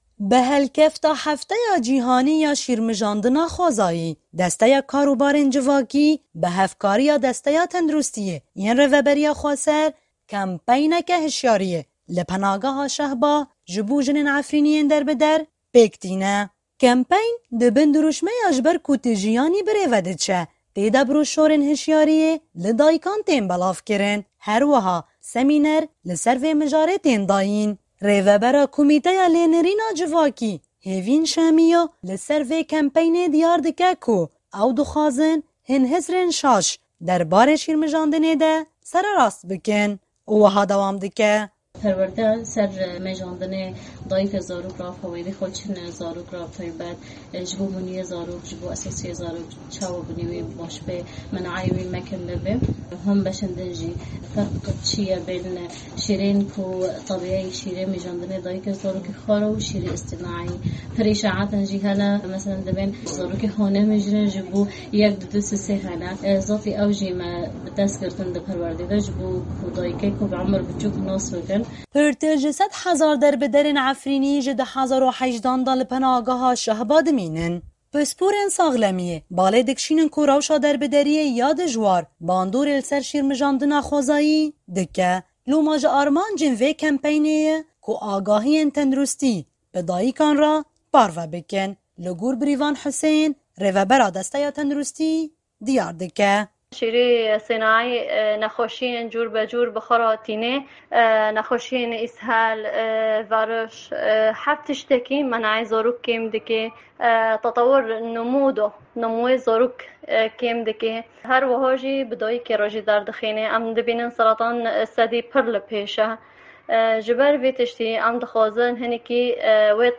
Rapora Efrînê_Kempeyna Şîrmijandina Xwezayî